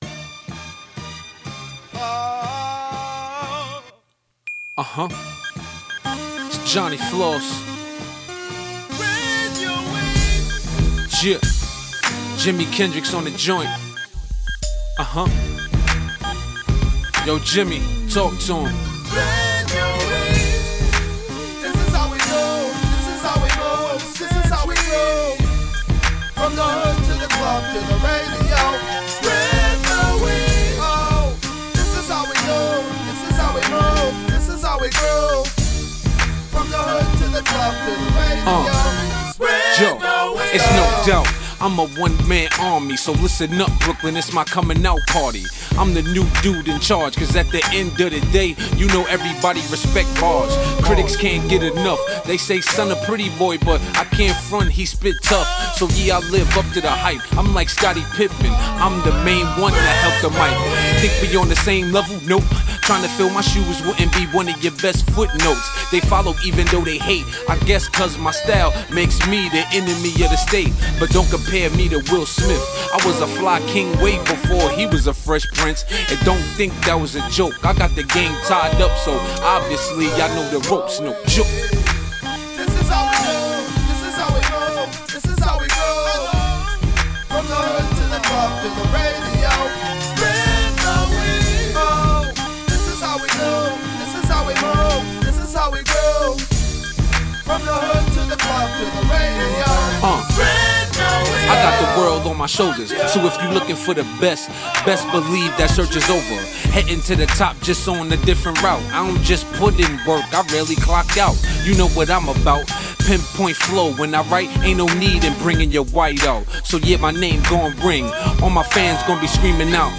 The production the flow everything yells old school hip hop.